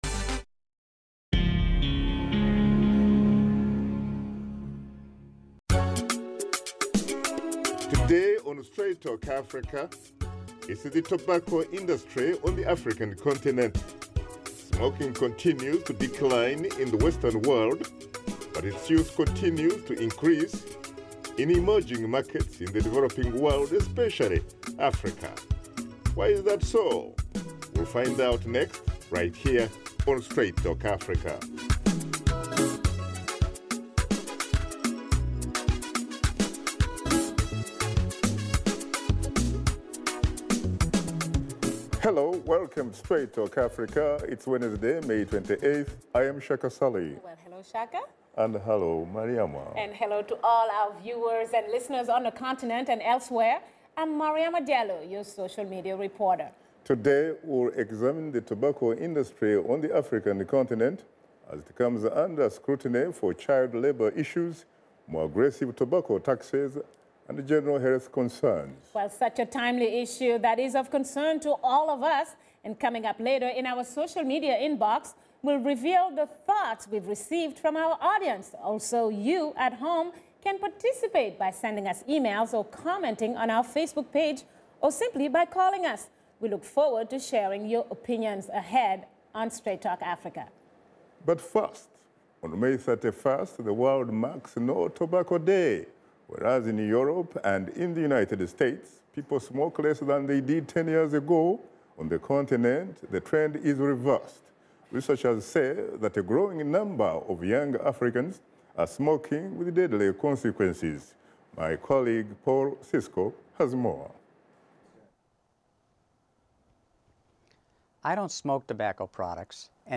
Host Shaka Ssali and his guests examine the tobacco industry on the African continent as it comes under scrutiny for child labor issues, more aggressive tobacco taxes, and general health concerns.